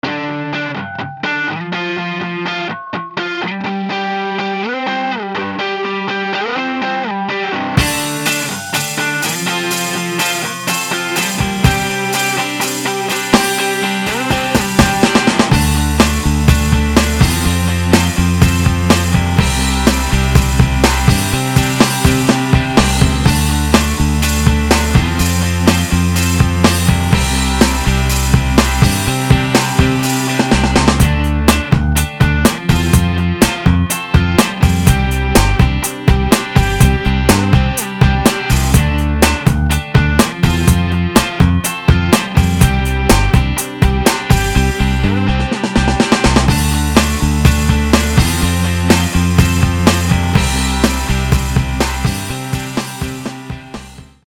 【Drums】
Hard_rock.mp3